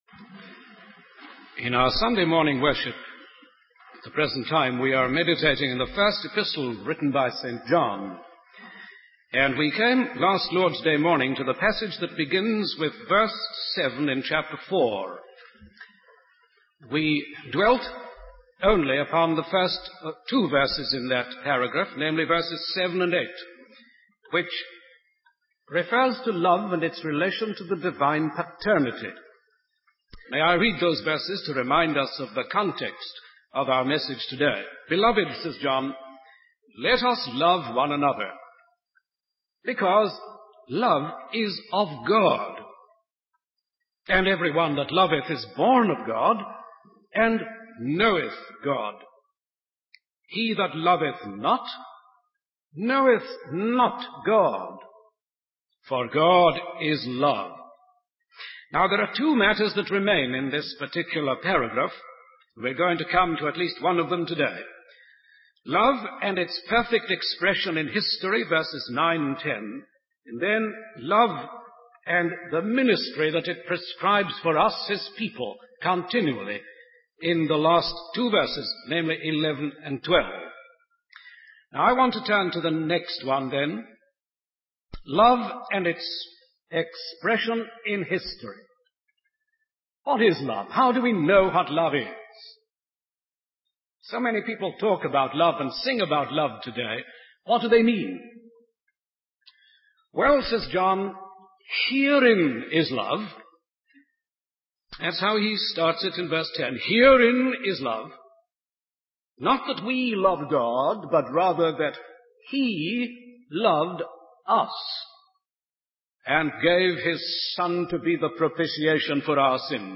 In this sermon, the preacher emphasizes the incredible love and sacrifice of God for humanity. He highlights the fact that God sent His only Son into the world to be an eternal sacrifice for our sins.
The sermon concludes with a prayer of gratitude for the sacrifice of Jesus and a reminder of the significance of His body and blood in the communion.